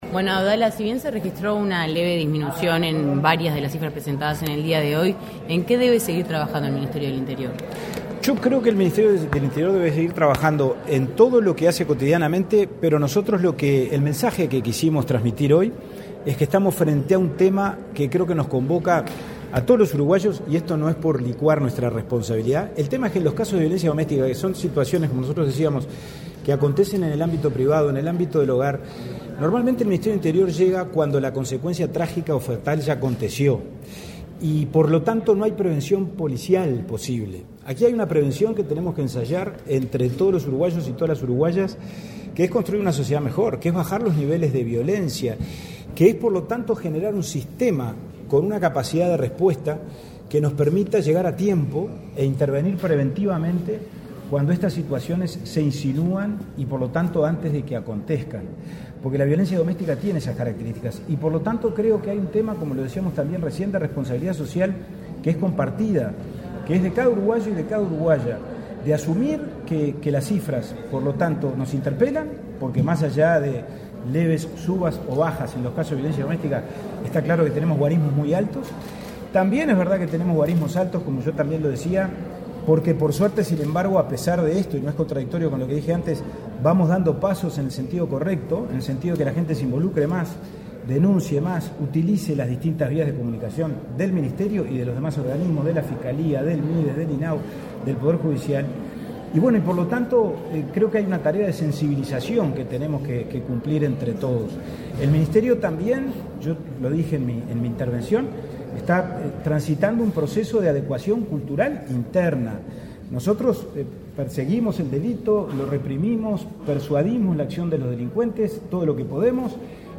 Declaraciones a la prensa del subsecretario del Ministerio del Interior, Pablo Abdala